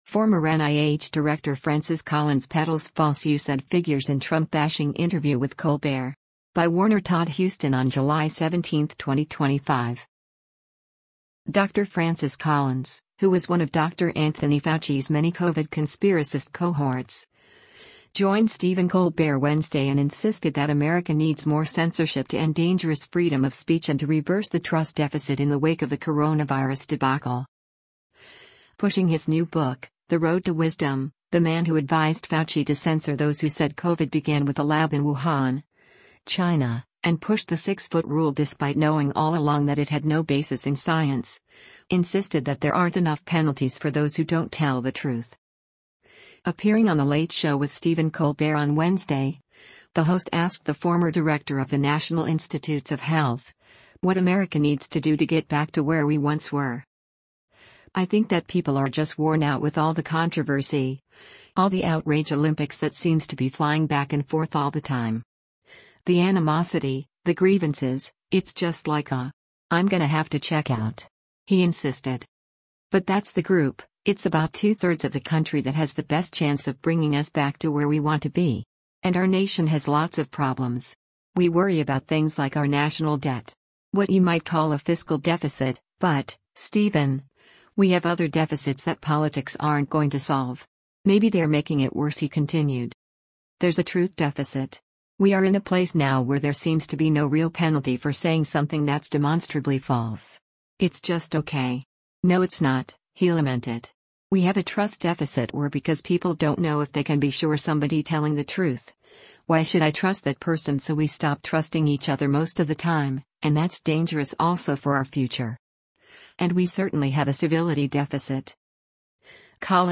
Former NIH Director Francis Collins Peddles False USAID Figures in Trump-Bashing Interview with 'Colbert'
Appearing on The Late Show with Stephen Colbert on Wednesday, the host asked the former director of the National Institutes of Health, what America needs to do to “get back” to where we once were.